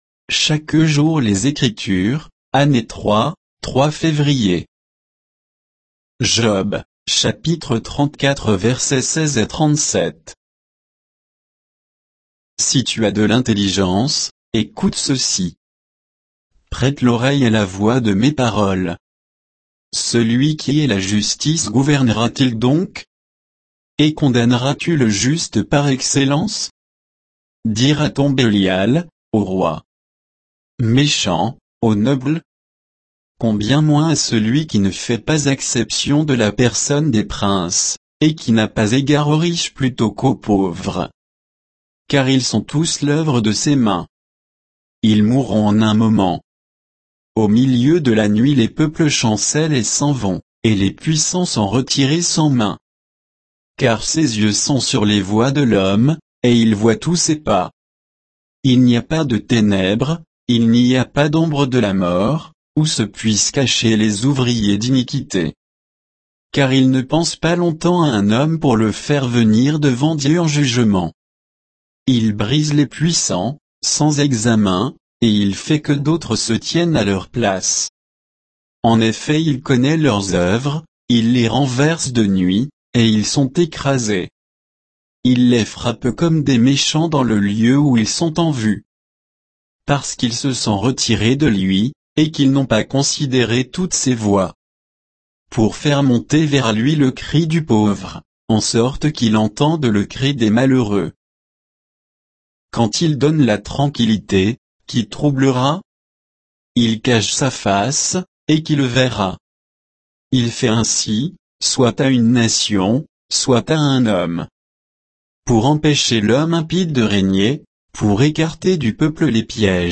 Méditation quoditienne de Chaque jour les Écritures sur Job 34, 16 à 37